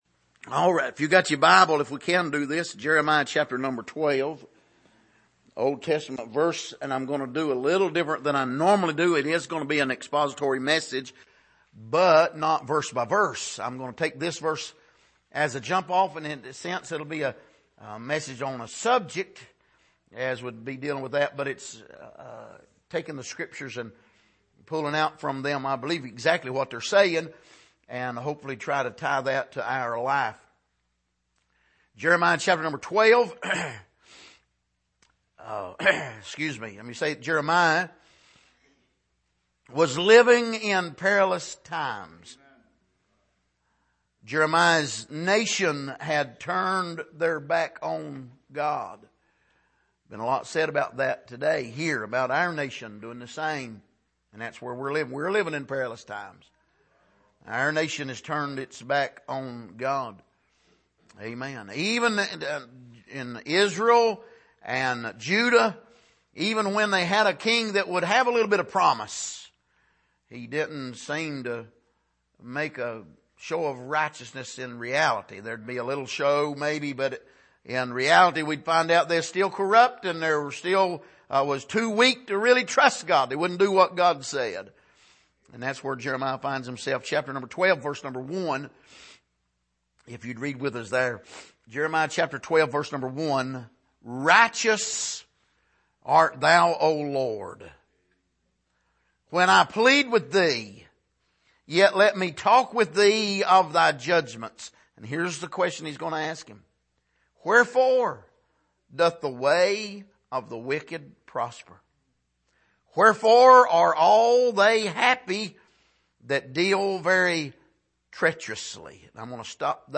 Passage: Jeremiah 12:1 Service: Sunday Morning